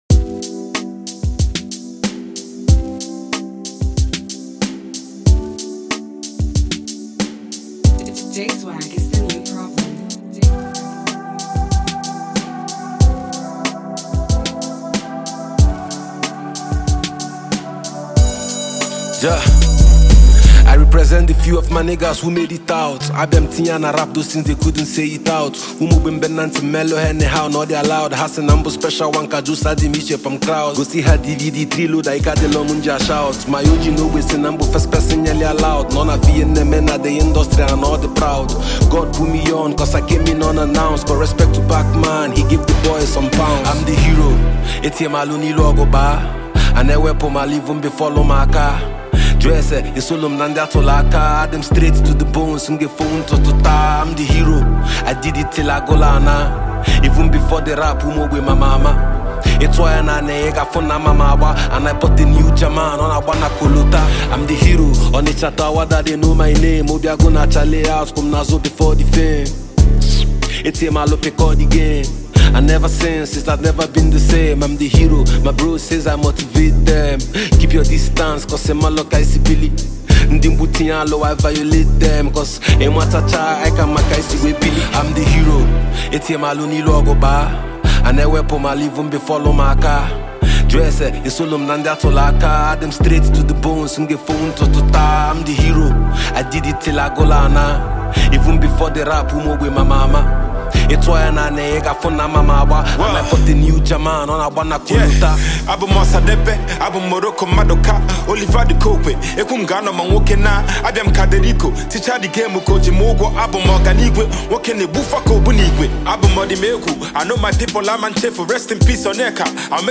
Super talented Nigerian Igbo rap artist